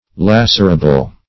Search Result for " lacerable" : The Collaborative International Dictionary of English v.0.48: Lacerable \Lac"er*a*ble\, a. [L. lacerabilis: cf. F. lac['e]rable.] That can be lacerated or torn.